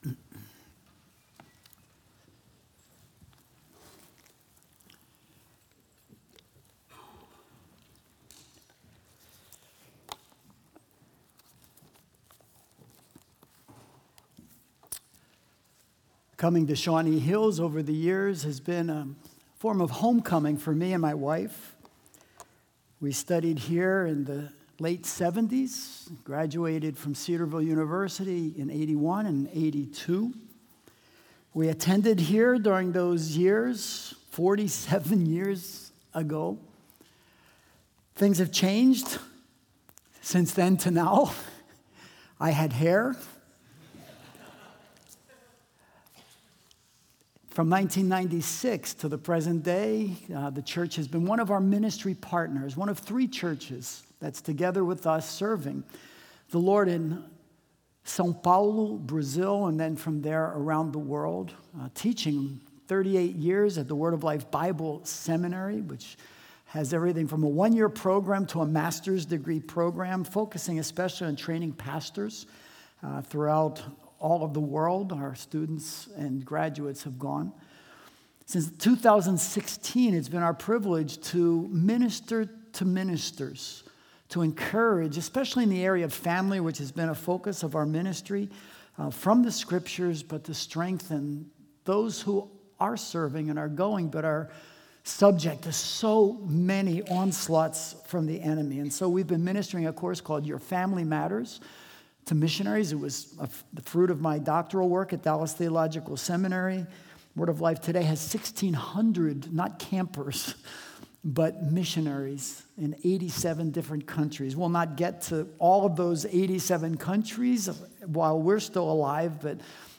His Mission Is Our Mission | Baptist Church in Jamestown, Ohio, dedicated to a spirit of unity, prayer, and spiritual growth